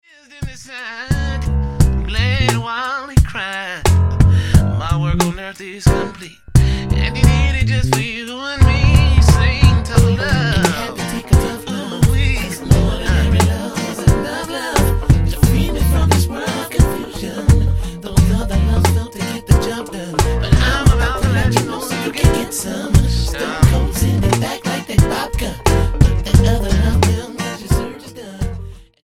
R&B
Style: Gospel